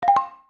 Category: Notification Ringtones